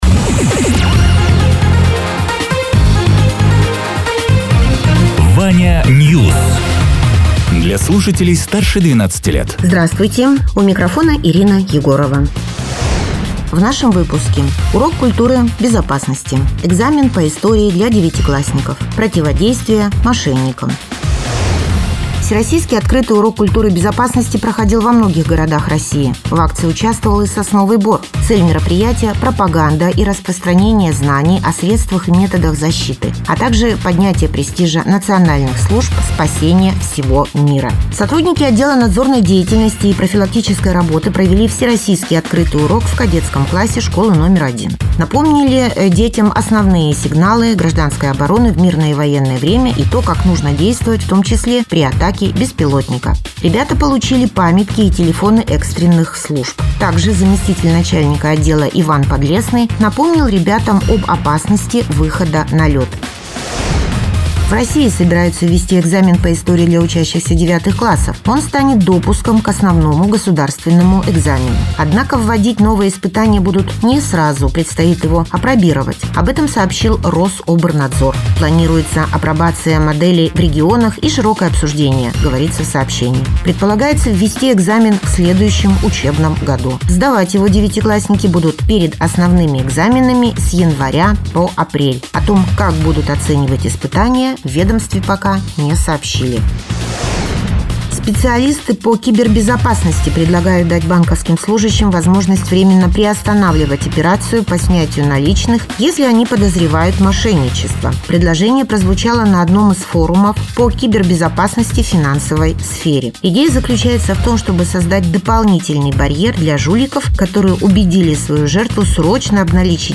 Радио ТЕРА 15.03.2026_12.00_Новости_Соснового_Бора